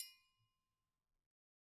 Triangle6-HitFM_v1_rr1_Sum.wav